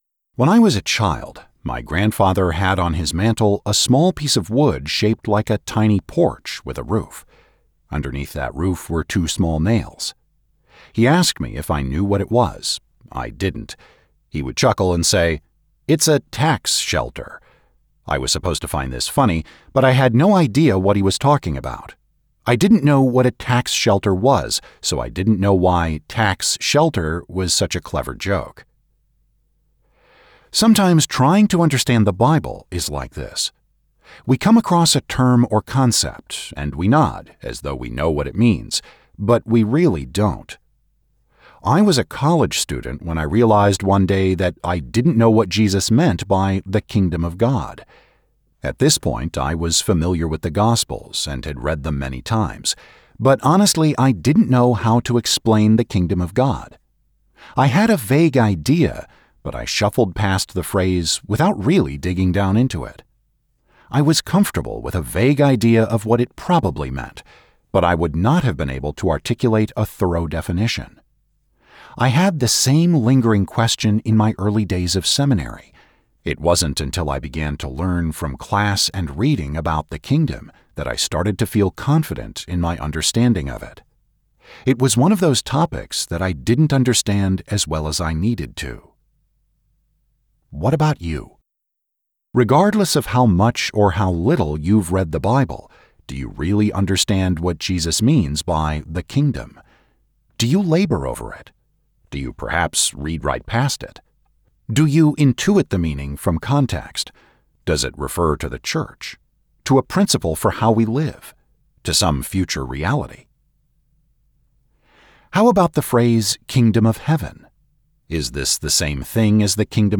Audiobook Download